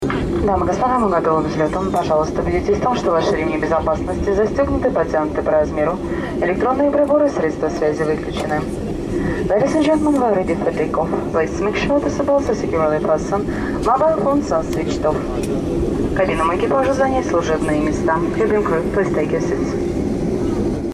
Звук голоса стюардессы на русском (мы готовы ко взлету)